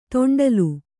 ♪ to'ṇḍalu